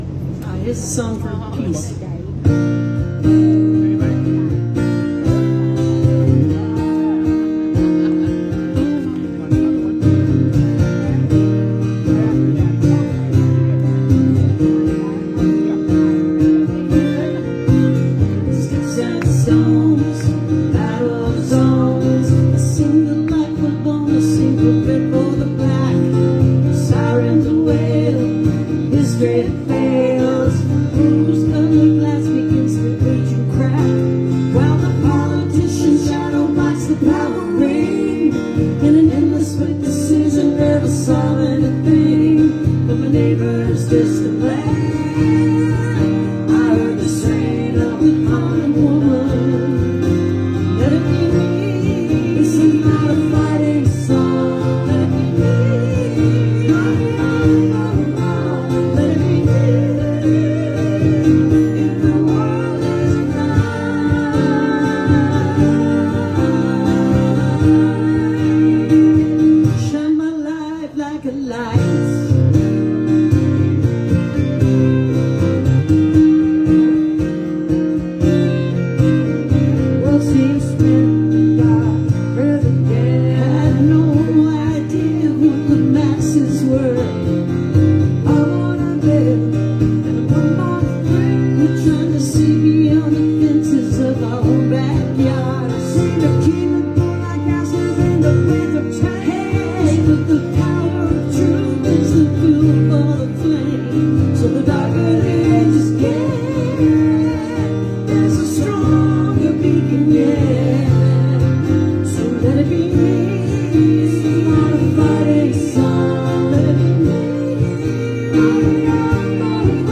(captured from a facebook livestream)